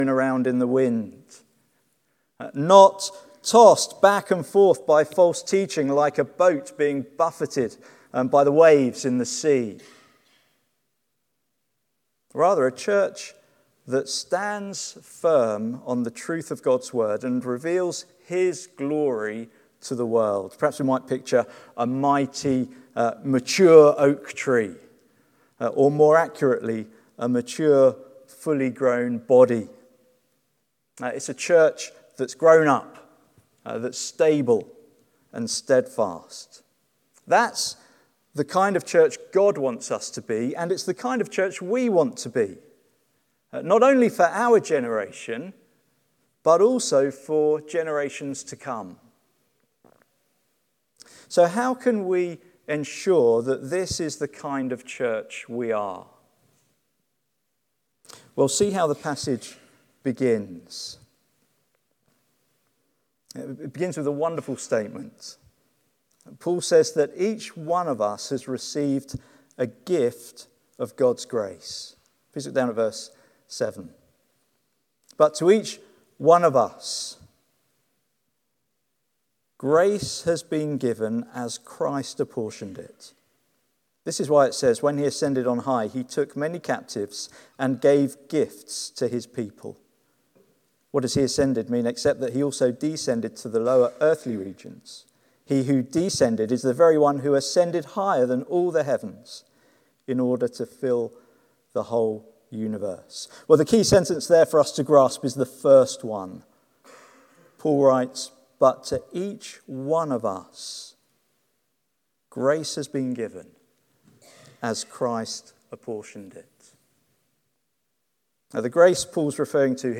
Note: Unfortunately, the opening few minutes of this sermon were missed from the recording.